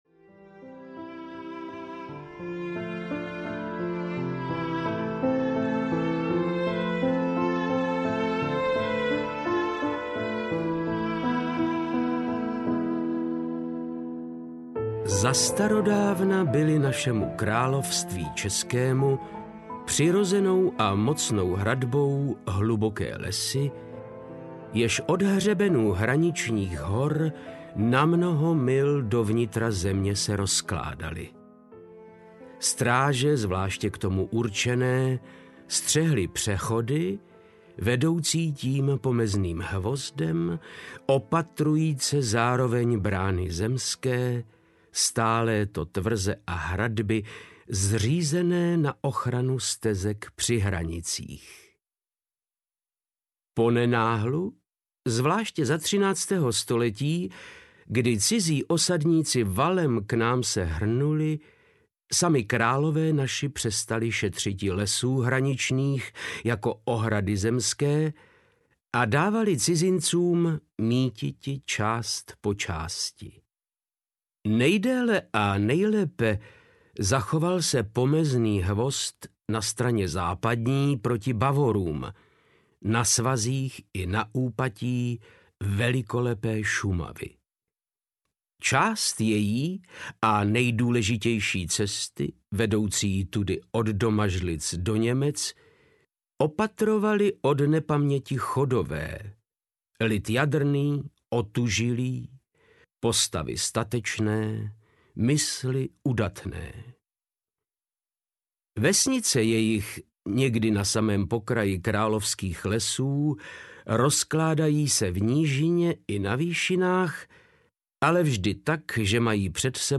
Psohlavci audiokniha
Ukázka z knihy
• InterpretVáclav Knop